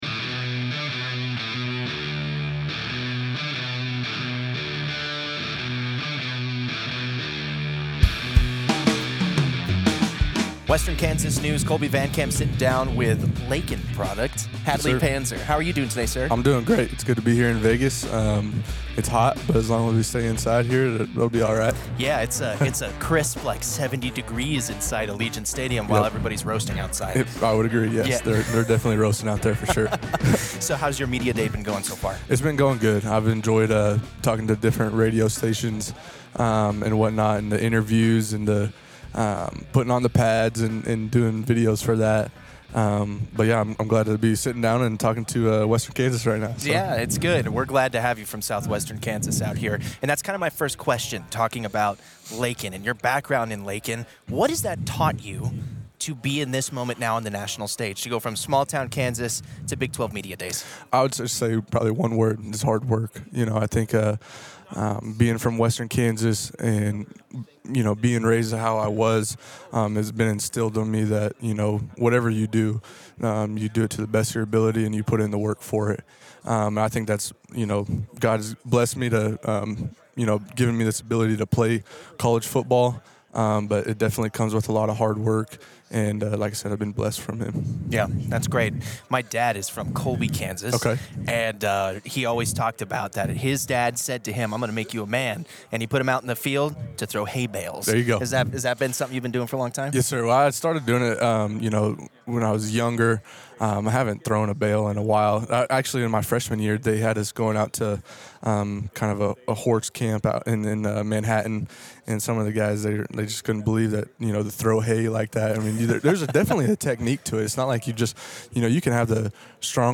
Big 12 Media Days: Exclusive interviews with Kansas State football players – Western Kansas News